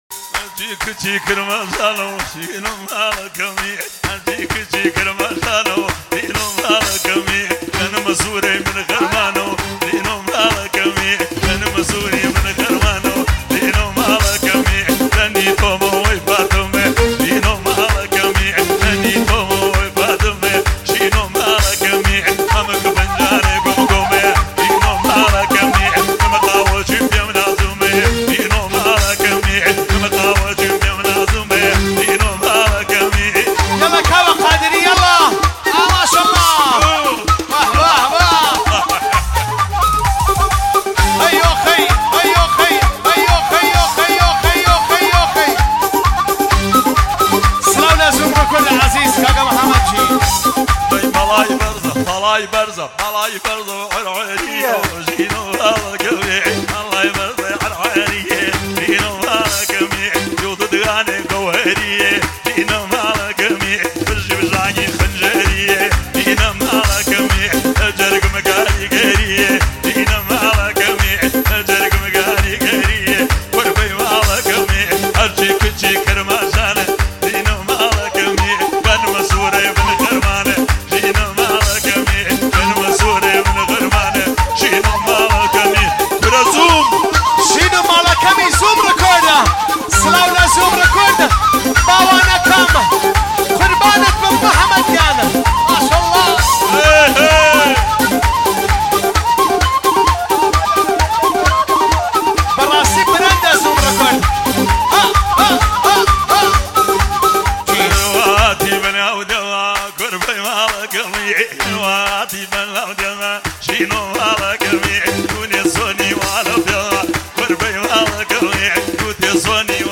هنگ شاد و پرانرژی
موسیقی کردی
ریتم شاد و ملودی جذاب